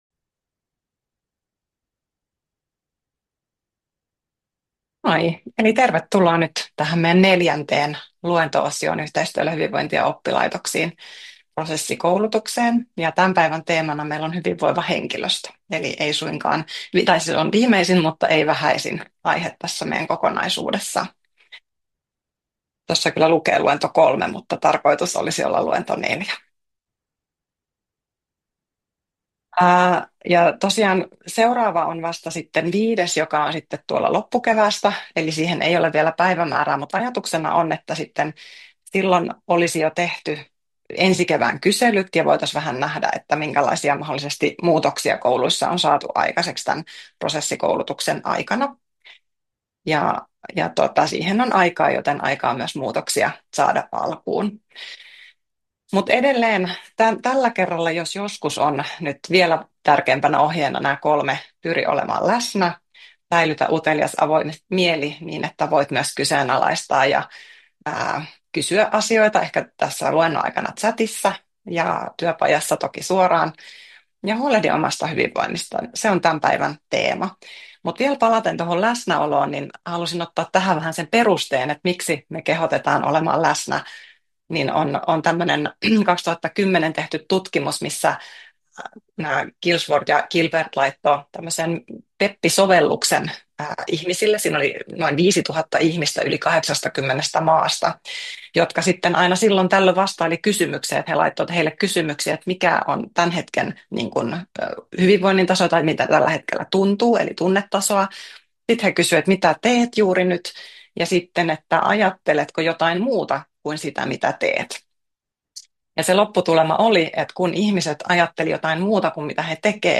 Yhteistyöllä hyvinvointia oppilaitoksiin, luento 4: Hyvinvoiva työyhteisö